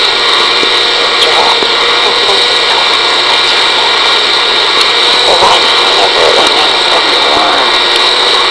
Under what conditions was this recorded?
We were there and heard this as it was happening. We werer at a local cemetery.